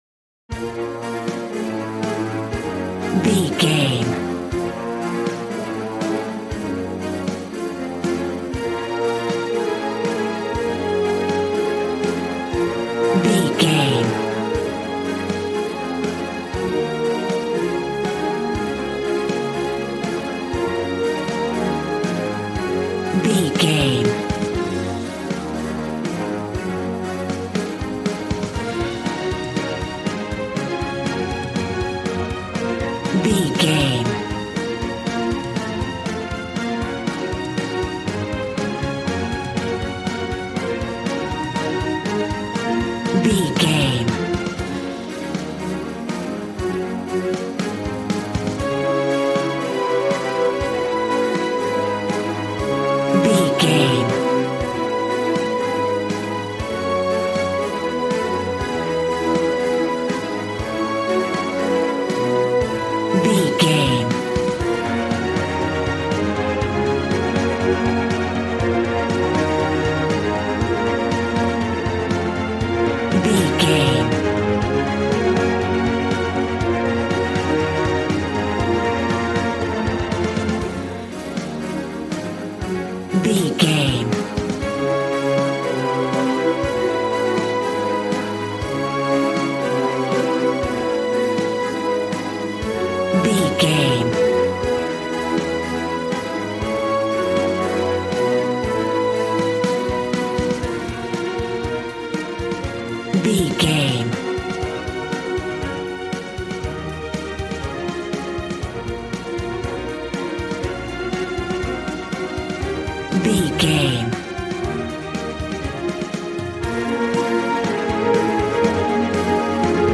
Aeolian/Minor
B♭
dramatic
strings
violin
brass